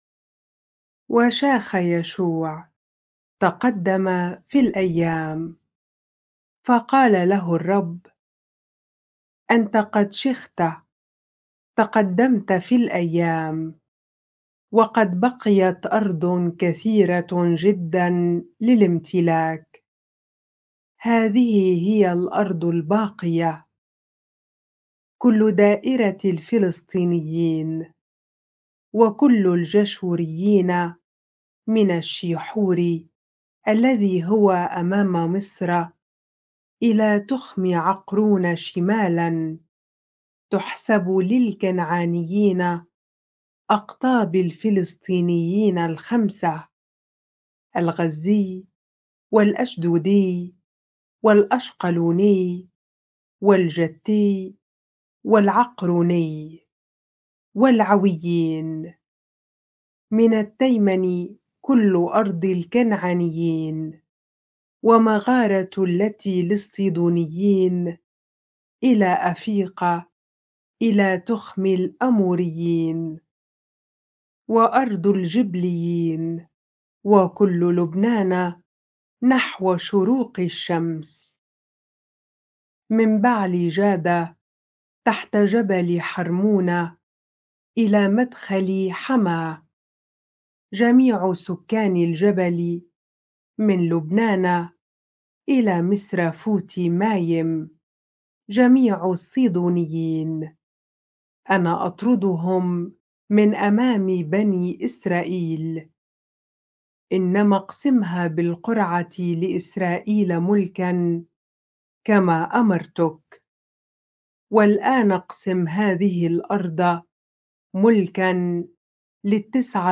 bible-reading-joshua 13 ar